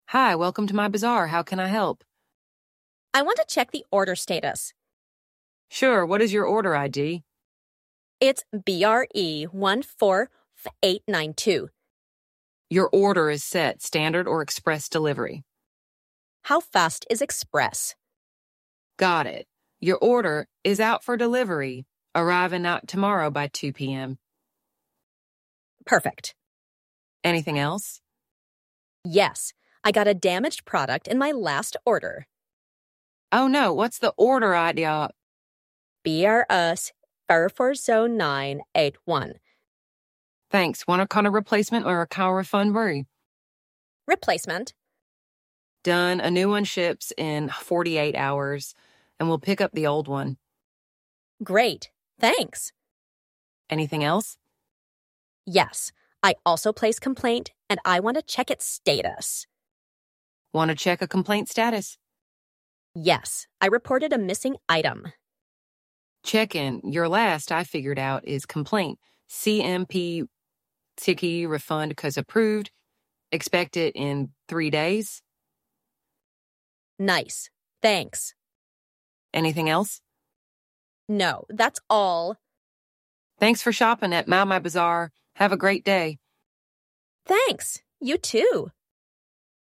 Experience AI-powered voice agents handling real-time calls.
🛒 E-Commerce AI Voice Agent